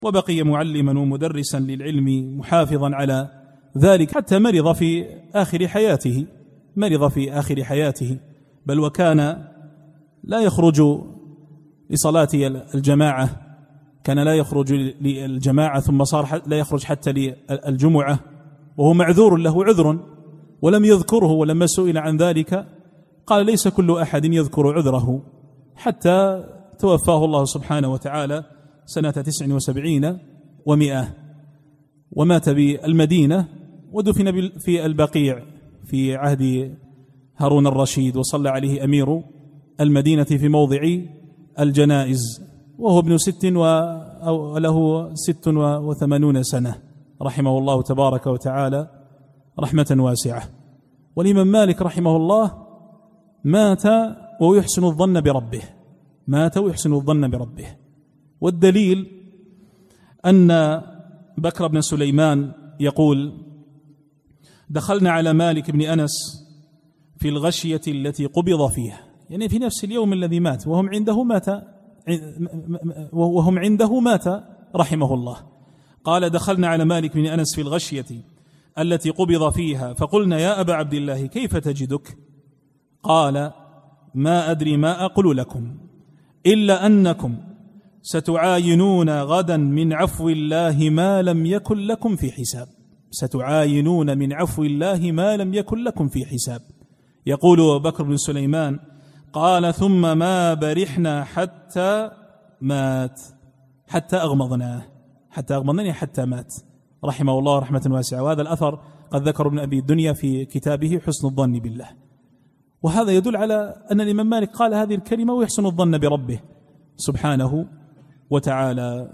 الإستماع - التحميل الدرس الأول